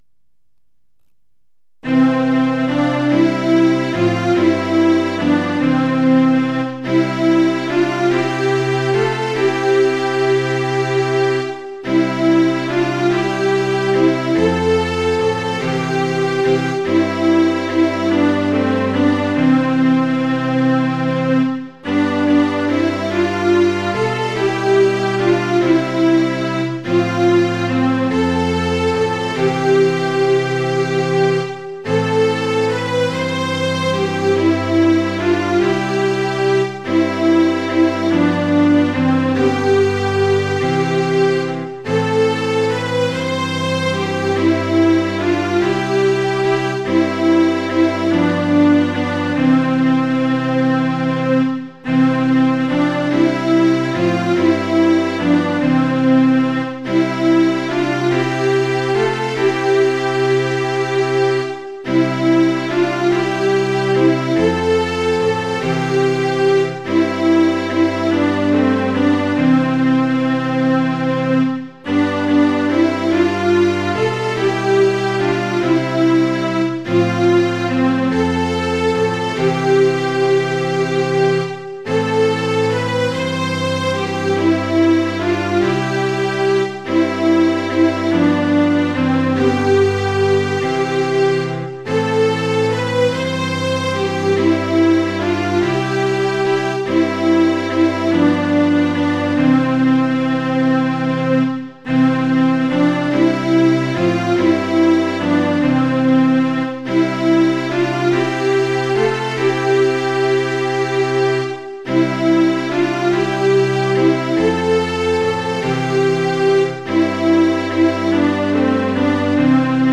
◆　８分の６拍子：　一拍目から始まります。